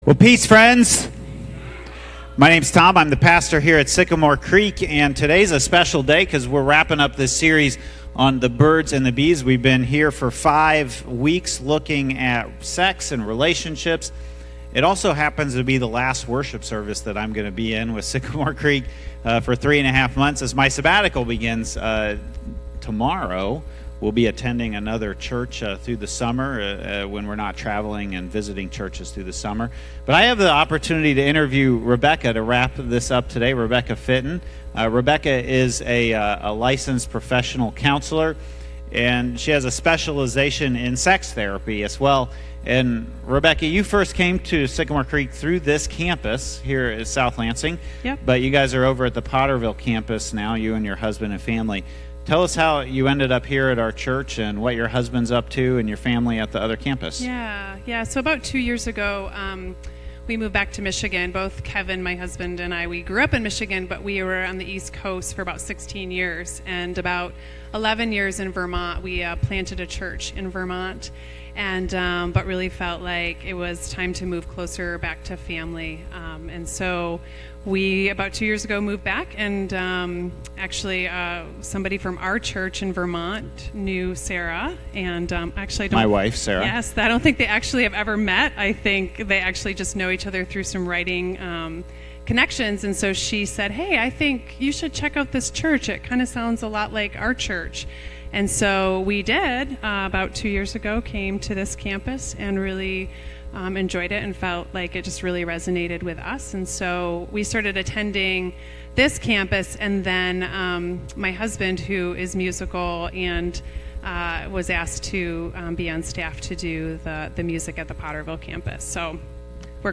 The Birds & The Bees – Interview With a Sex Therapist
the-birds-the-bees-interview-with-a-sex-therapist.mp3